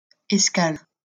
Escales (French pronunciation: [ɛskal]